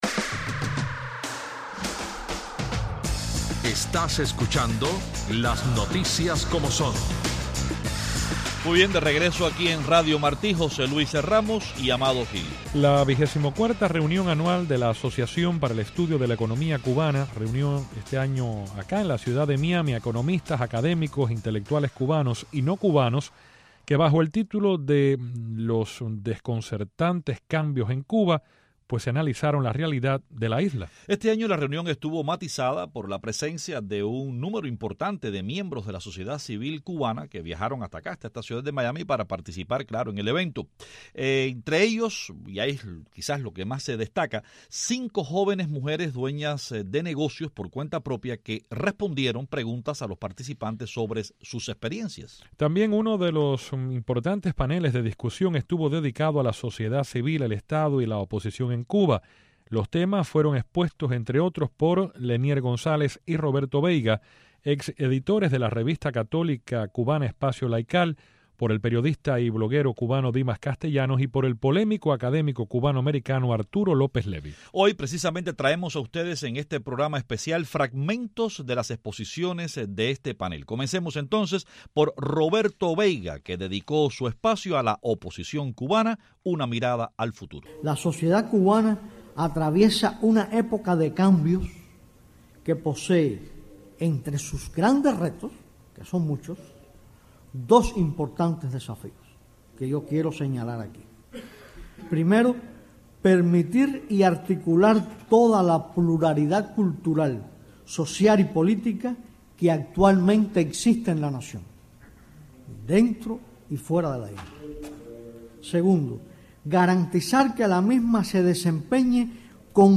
Uno de los más importantes paneles de discusión de la reunión anual de la Asociación para el Estudio de la Economía Cubana celebrado en Miami estuvo dedicado a la Sociedad Civil, el Estado y la Oposición en Cuba. Hoy ofrecemos fragmentos de las exposiciones de los expertos participando en el panel.